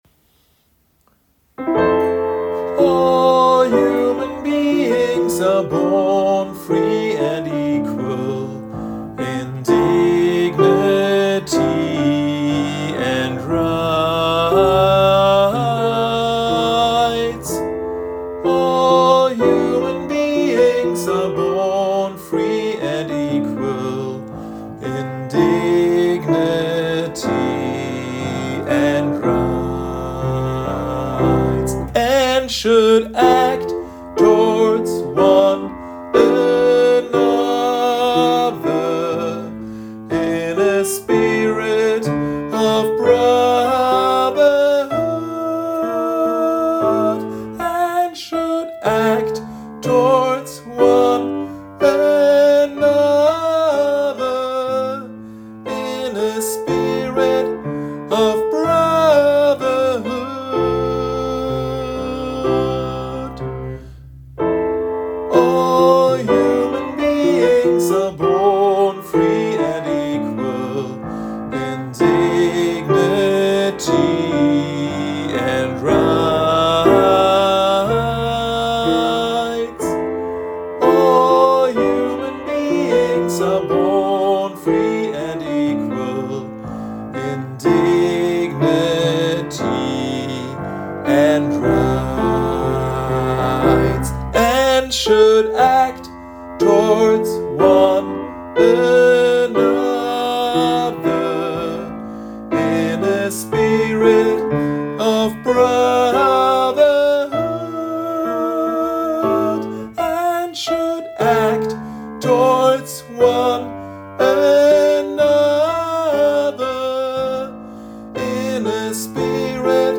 Übe-Dateien
SOPRAN
Article01_Gospel_S.mp3